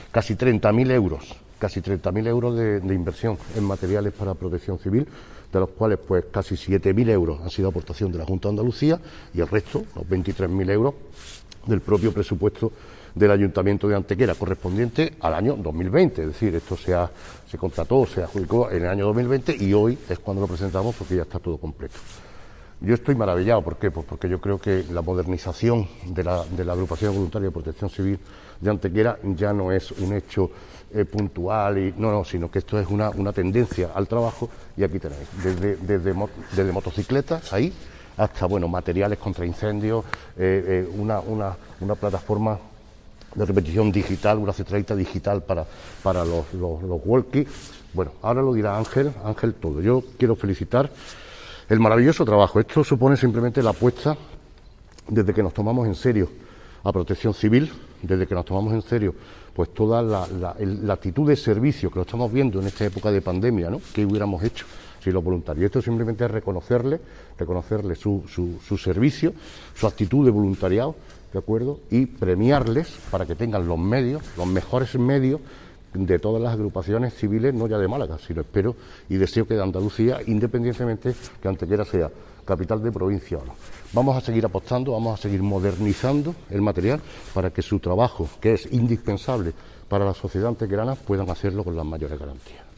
ENLACE A VÍDEO DE LA RUEDA DE PRENSA EN YOUTUBE
Cortes de voz